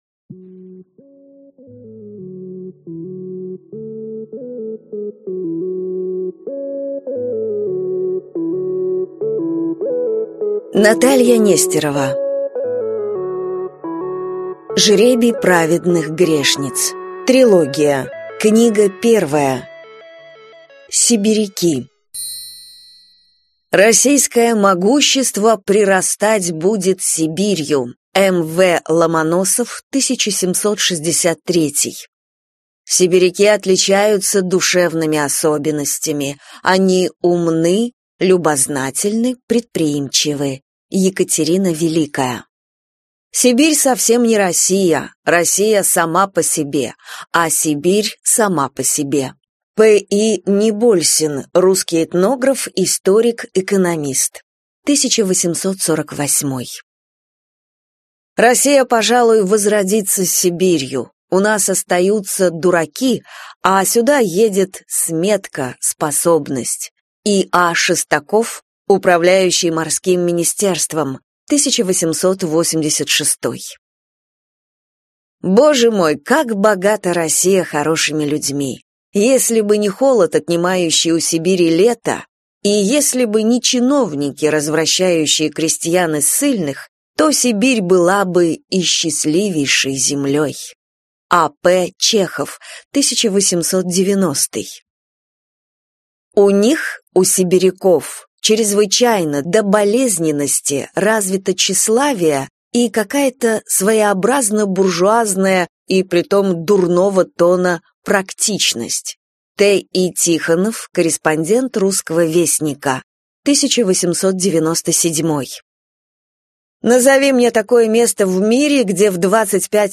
Аудиокнига Жребий праведных грешниц (сборник) | Библиотека аудиокниг